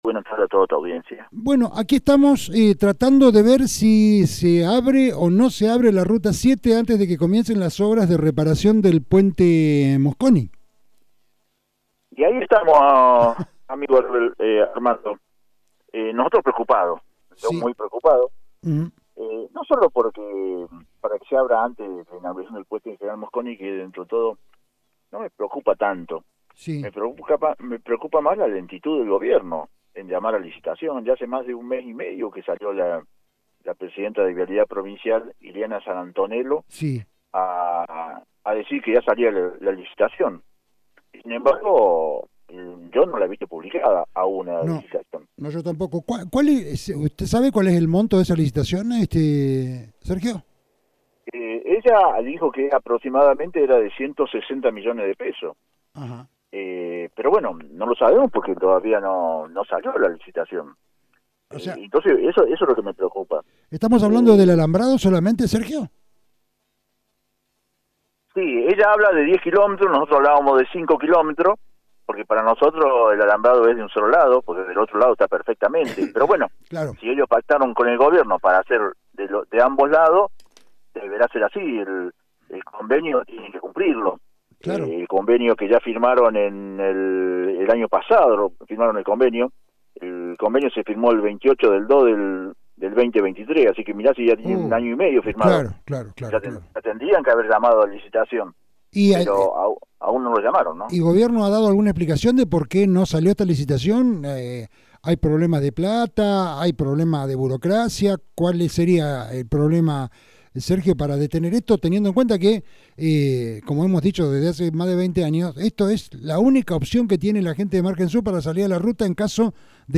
Fuente: Resumen Económico, Radio Provincia.